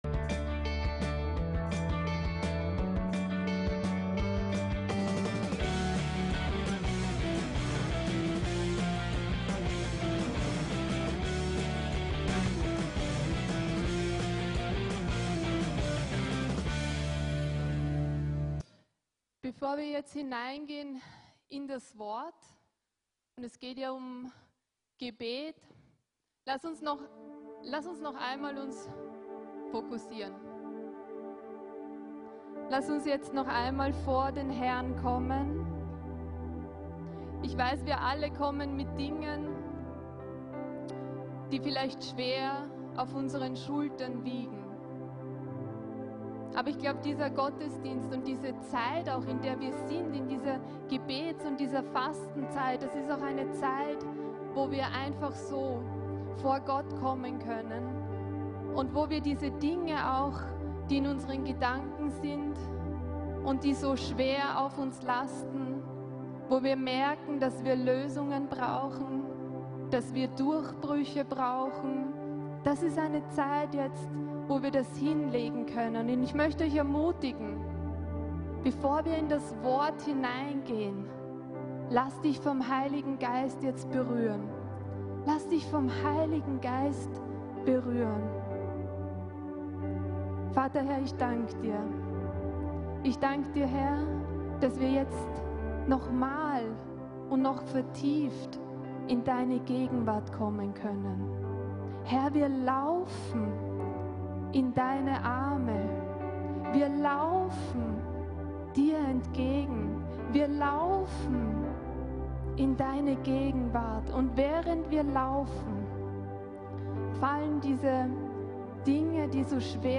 GEBET IST BEZIEHUNG ~ VCC JesusZentrum Gottesdienste (audio) Podcast